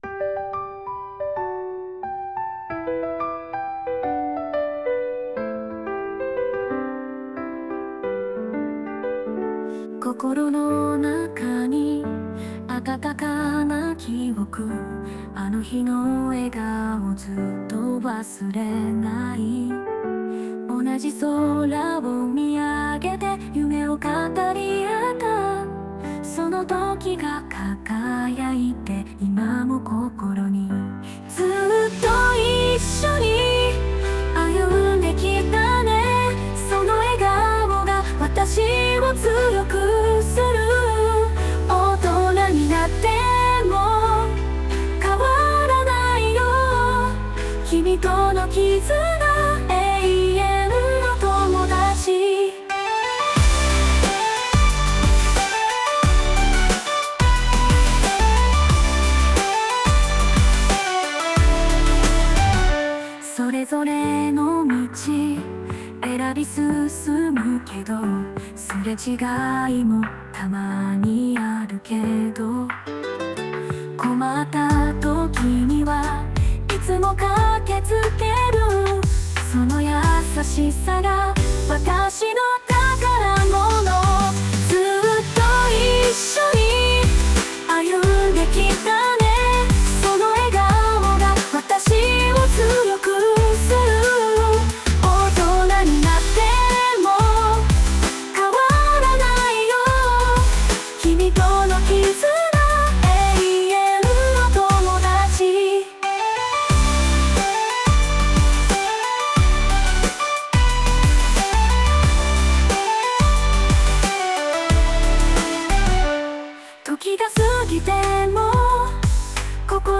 女性ボーカル邦楽 女性ボーカル余興
著作権フリーBGMです。
女性ボーカル（邦楽）曲です。